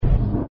dooropen.mp3.svn-base